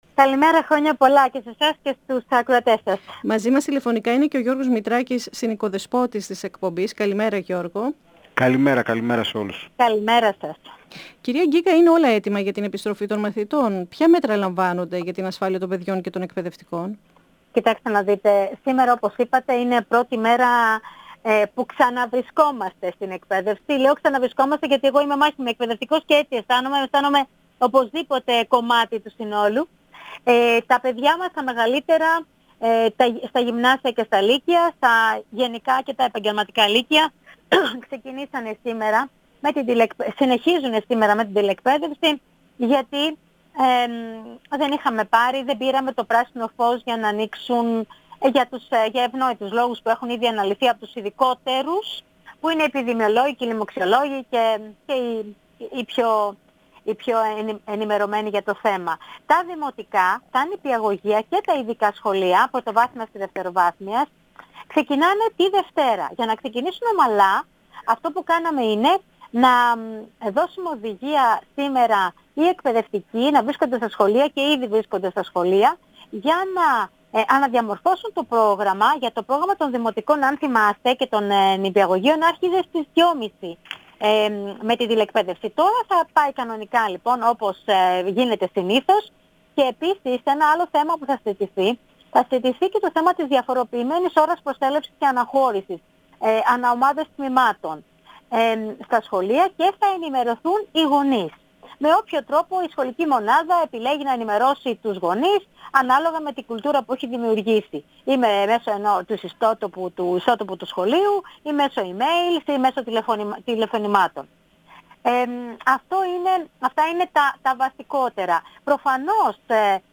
H Γενική Γραμματέας του Υπουργείου Παιδείας Αναστασία Γκίκα, στον 102 fm της ΕΡΤ3
Αναφερόμενη στην εισήγηση της επιτροπής των ειδικών για το άνοιγμα των δημοτικών και των νηπιαγωγείων στις 11 Ιανουαρίου, υποστήριξε ότι ήταν ομόφωνη. 102FM Συνεντεύξεις ΕΡΤ3